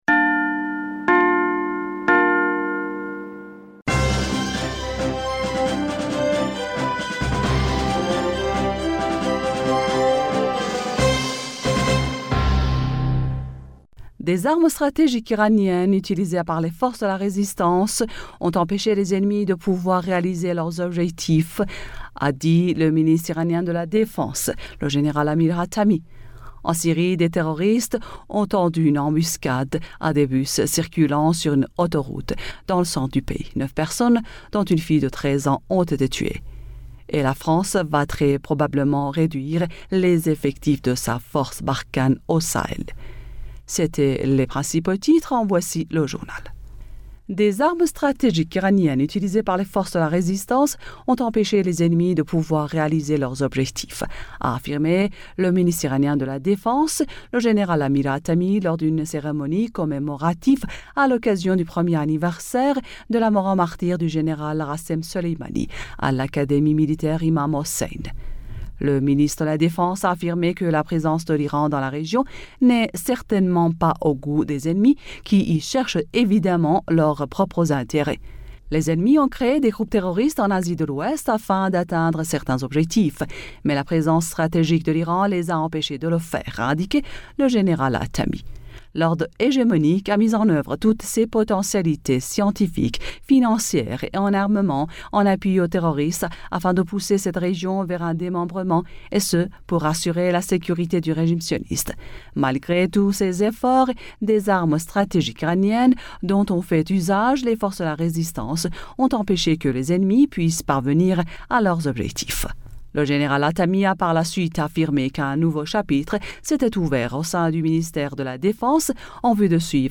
Bulletin d'informationd u 04 Janvier 2021